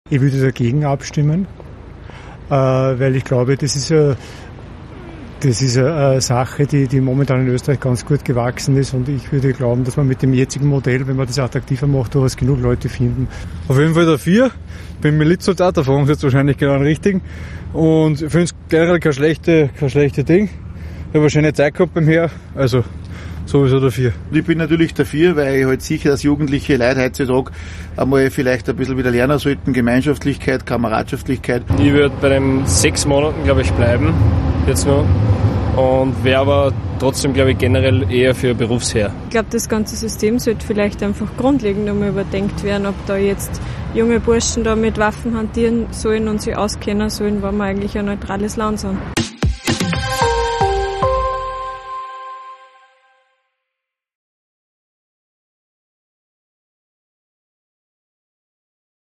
AUF1 hat sich in Linz umgehört.